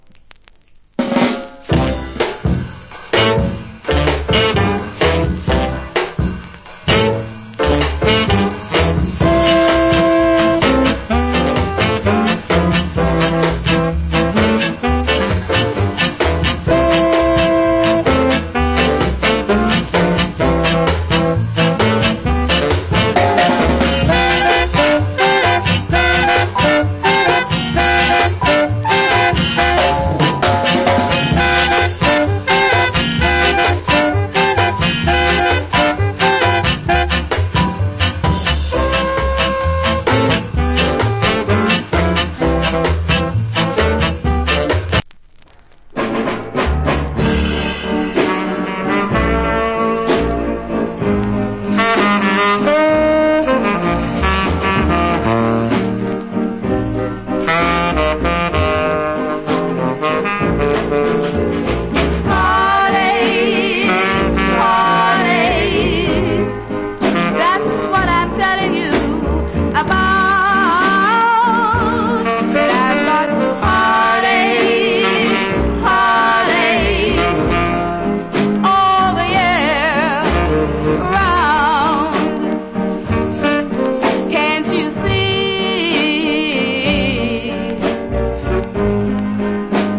Great Ska Inst.